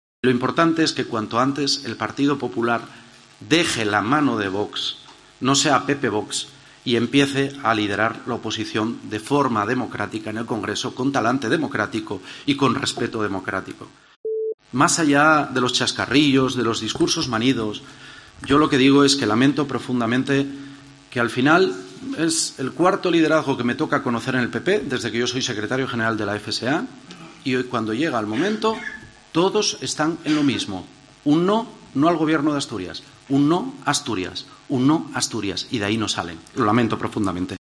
Durante su intervención ante el Comité Autonómico de la FSA-PSOE, celebrado en el Pozo Sotón, el líder de los socialistas asturianos también ha aprovechado para exigir públicamente la "inmediata dimisión" al concejal madrileño Javier Ortega Smith, después de que como portavoz municipal de Vox se encarase y tirara unos papeles y una botella de agua vacía al edil Eduardo Fernández Rubiño.